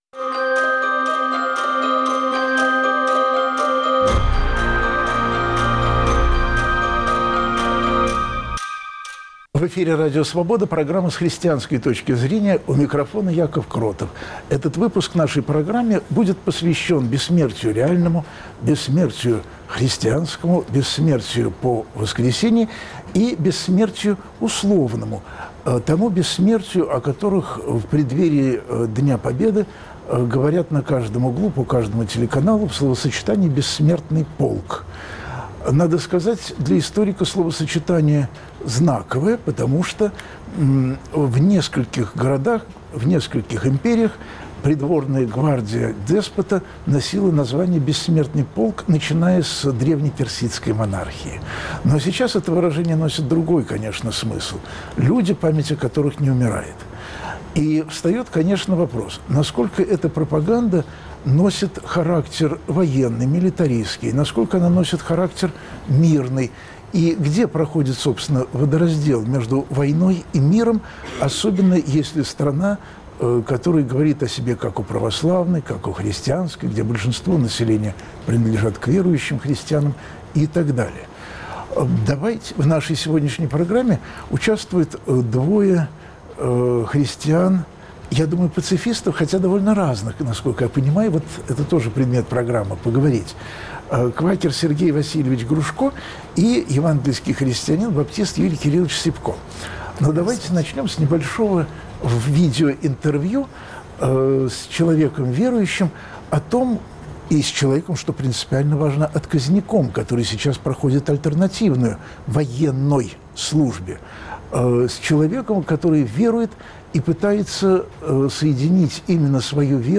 Почему победа в войне – не главное для христианина? В студии "Свободы" ответы на этот вопрос ищут двое пацифистов - квакер и баптист